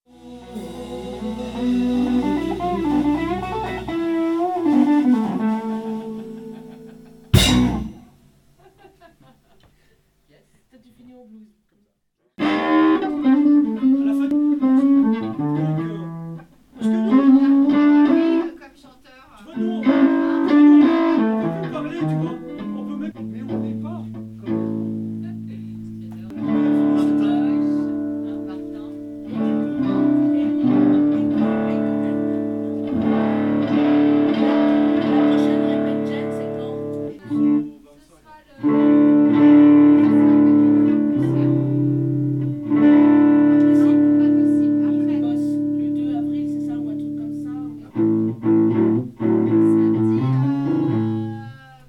Le Champ quand à lui sonne bien. Une bonne saturation bien (bas) medium.
Voici un petit essai que j'ai fait à la fin d'une répet, avec une strat sur le micro manche :